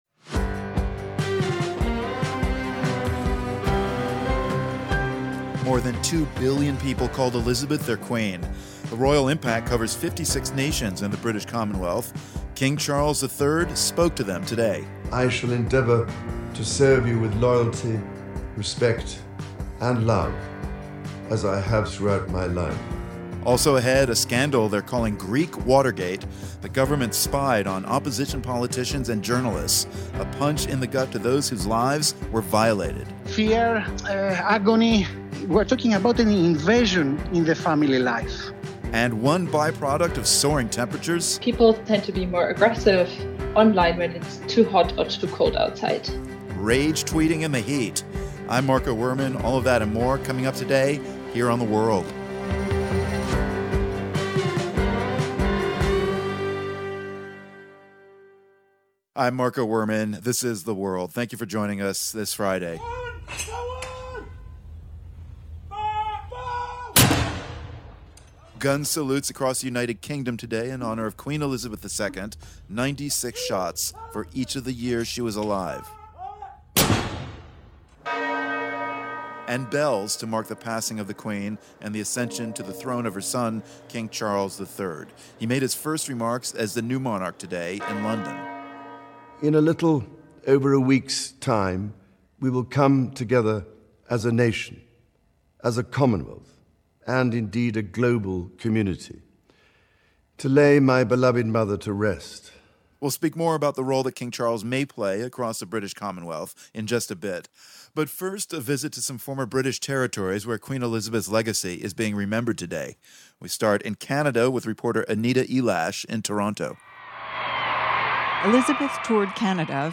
We hear from reporters in Canada, India, Kenya and Ghana about the queen’s influence there. And a relatively new spyware known as Predator is at the center of a European wiretapping scandal that has led to several resignations of political leaders in Greece.